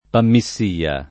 vai all'elenco alfabetico delle voci ingrandisci il carattere 100% rimpicciolisci il carattere stampa invia tramite posta elettronica codividi su Facebook panmissia [ pammi SS& a ] o pammissia [ id. ] o panmixia [ pammik S& a ] s. f. (biol.)